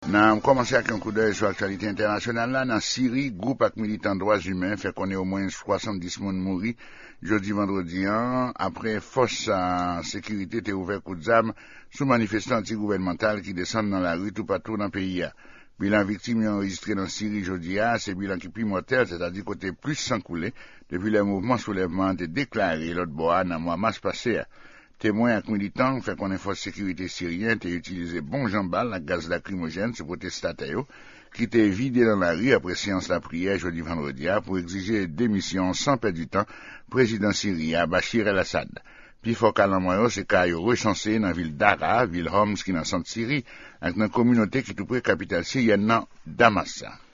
which is spoken mainly in Haiti The recording comes from the Voice of America .